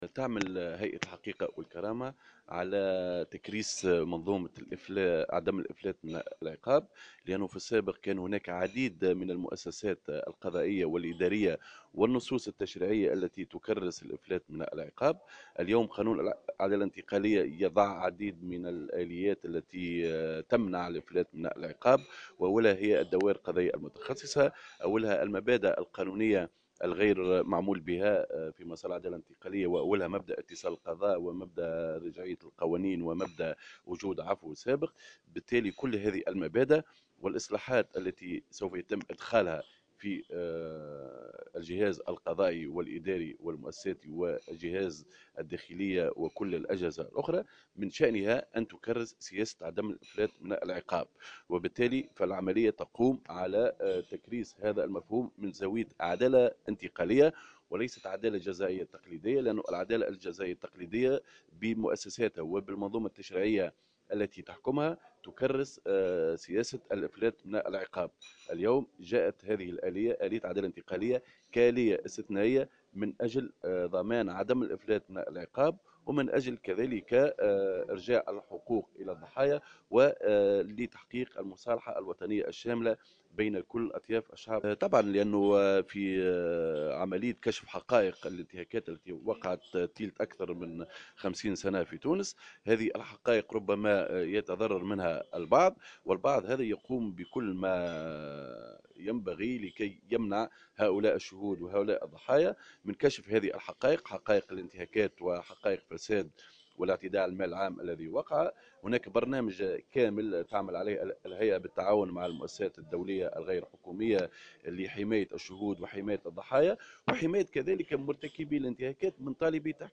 أكد رئيس لجنة التحكيم والمصالحة بهيئة الحقيقة والكرامة خالد الكريشي في تصريح لمراسلة الجوهرة "اف ام" أن الهيئة تعمل على تكريس منظومة عدم الإفلات من العقاب لأنه في السابق كان هناك العديد من النصوص والتشريعات التي تكرس مبدأ الإفلات من العقاب.